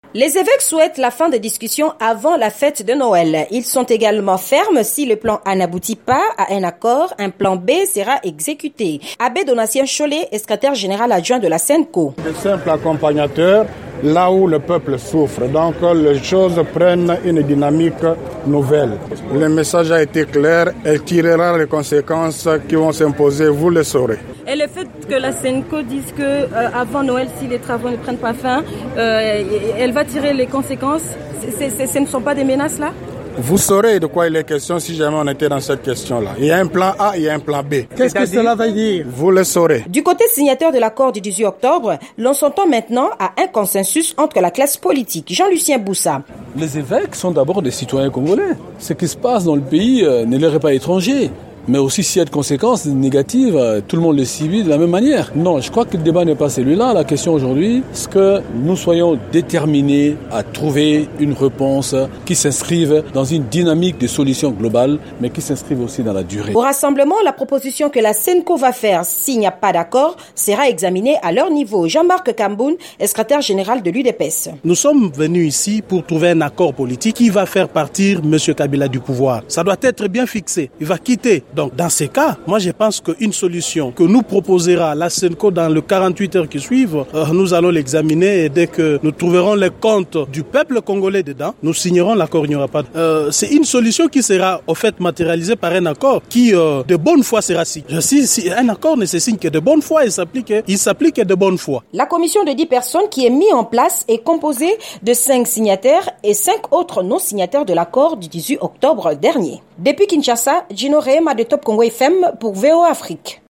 L'ultimatum des évêques catholiques à la classe politique en RDC-Reportage VOA/Congo Top